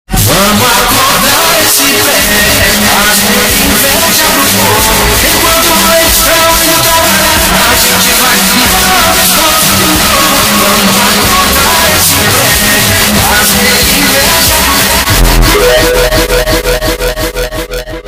2025-02-09 22:34:07 Gênero: Phonk Views